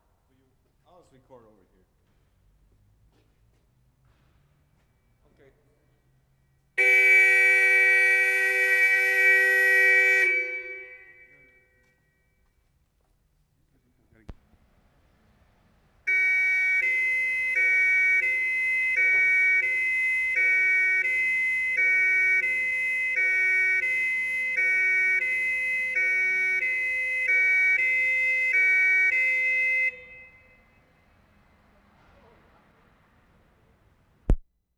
5 - 11. SIRENS (5-6 police siren, 7-11 fire sirens).
7 - 11. All recorded inside garage.